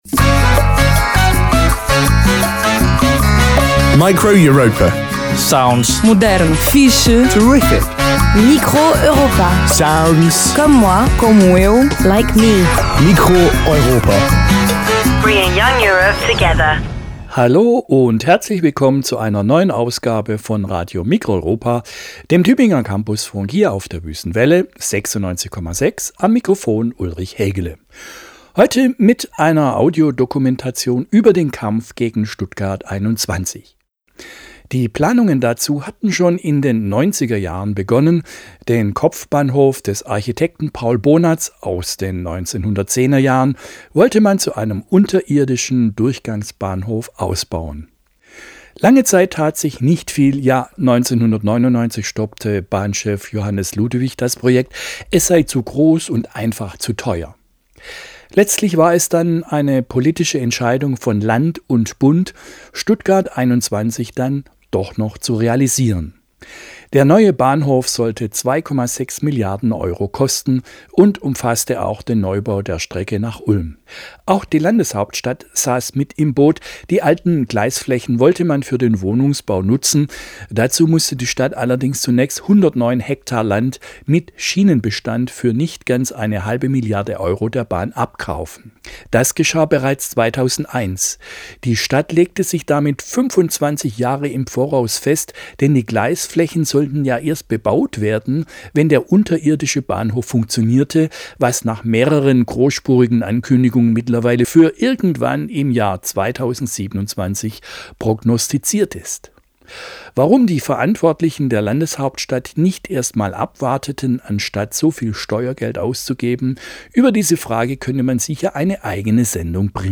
15 Jahre Kampf gegen Stuttgart 21. Audiodokumentation der Kundgebung vom 1. Oktober 2010